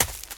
HauntedBloodlines/STEPS Leaves, Run 29.wav at 545eca8660d2c2e22b6407fd85aed6f5aa47d605
STEPS Leaves, Run 29.wav